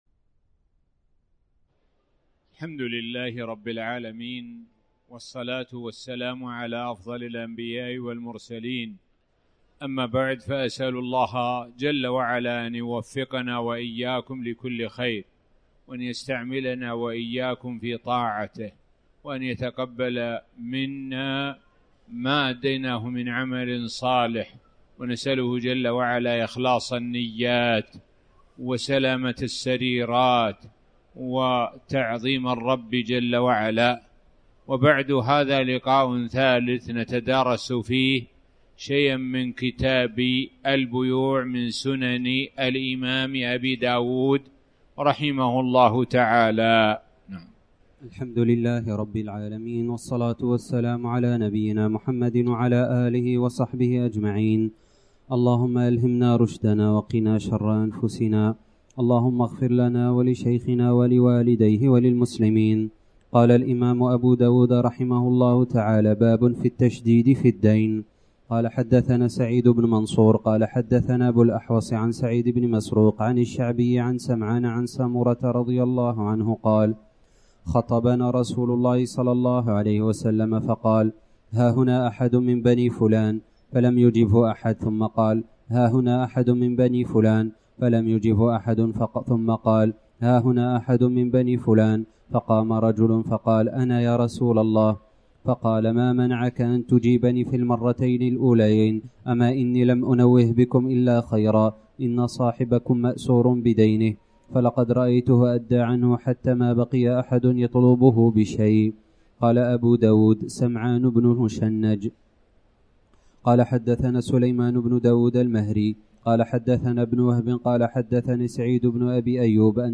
تاريخ النشر ٣ ذو الحجة ١٤٣٩ هـ المكان: المسجد الحرام الشيخ: معالي الشيخ د. سعد بن ناصر الشثري معالي الشيخ د. سعد بن ناصر الشثري باب التشديد في الدين